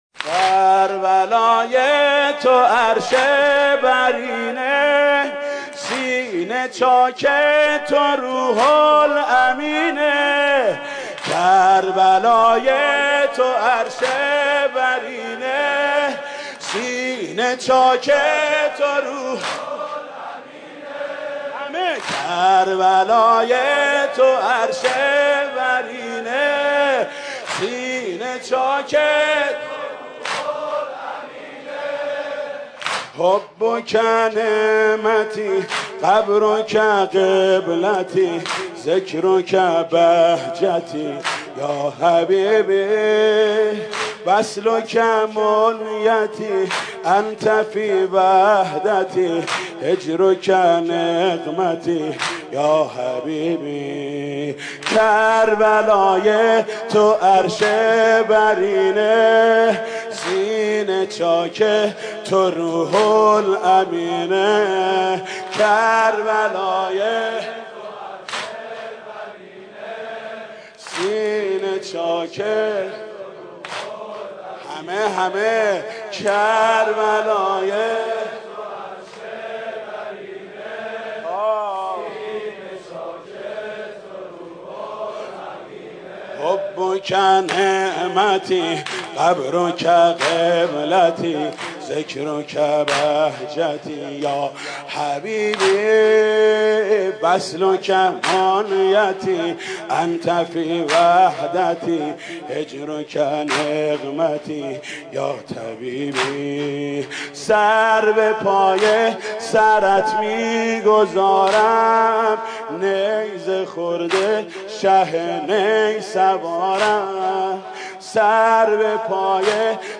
نوحه معروف عربی - فارسی - واحد سینه زنی با صدای حاج محمود کریمی -( کربلای تو عرش برینه )
اجرا شده توسط حاج محمود کریمی .
این نوحه در سال 84 یا 85 توسط مداح اهل بیت حاج محمود کریمی خونده شده